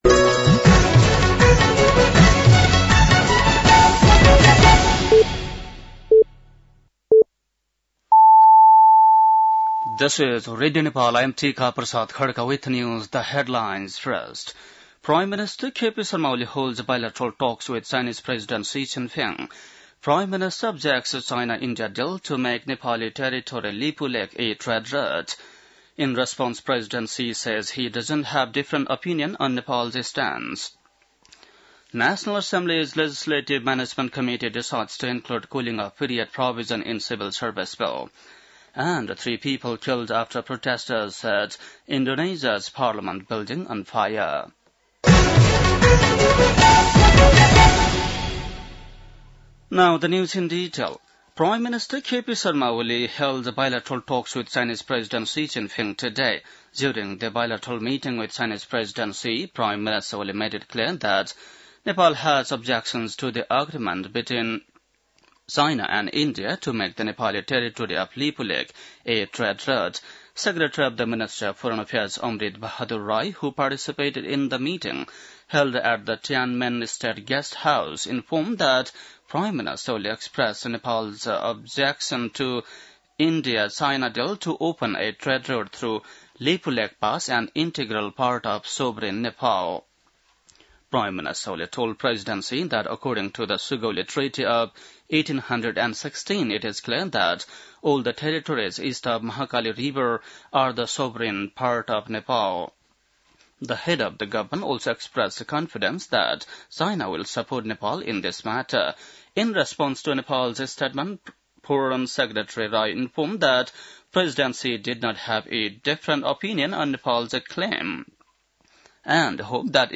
बेलुकी ८ बजेको अङ्ग्रेजी समाचार : १४ भदौ , २०८२